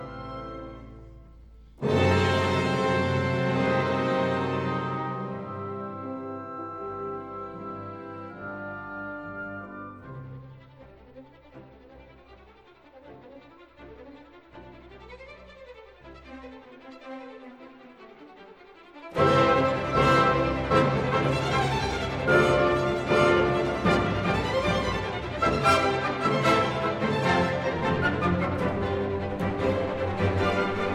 "templateExpression" => "Musique orchestrale"